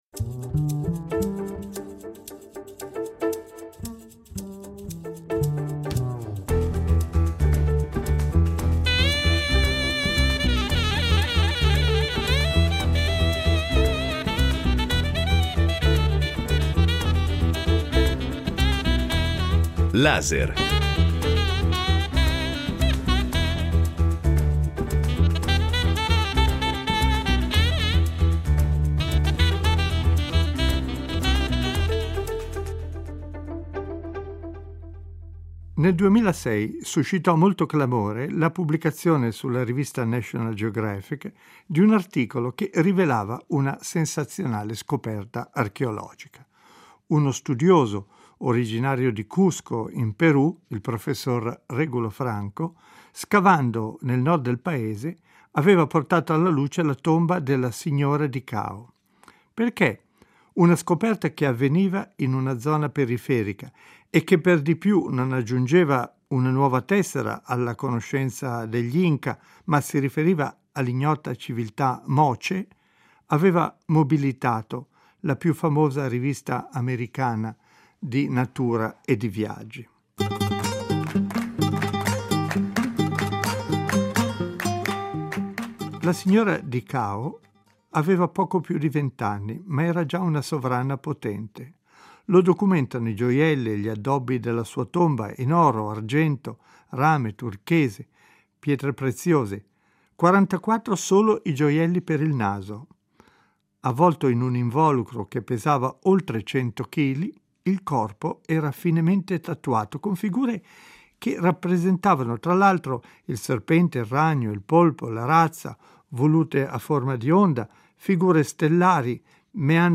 Ospiti della trasmissione saranno anche due donne archeologhe.